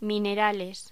Locución: Minerales
voz